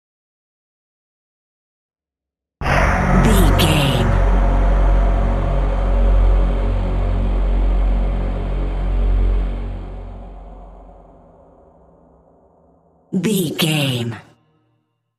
Dramatic Hit Trailer
Sound Effects
Epic / Action
Fast paced
In-crescendo
Atonal
heavy
intense
dark
aggressive
hits